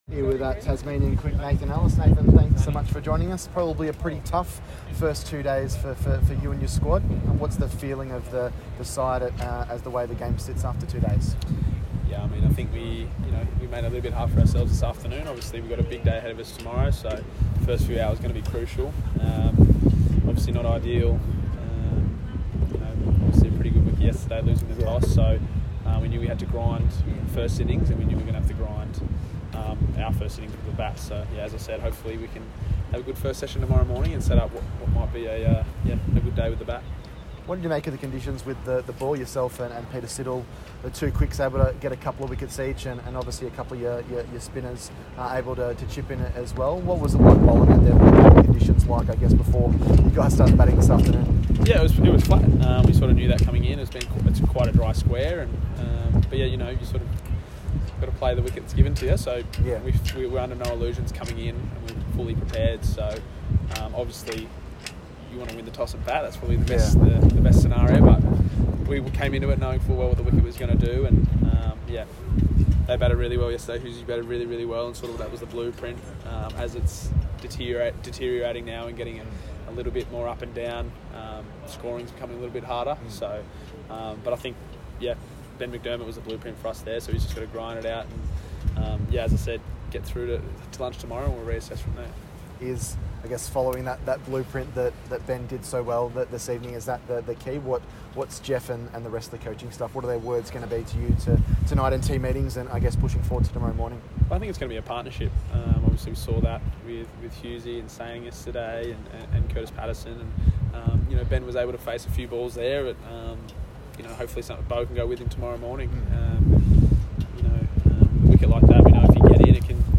Nathan Ellis spoke the media following Day 2 of NSW v TAS Sheffield Shield at the SCG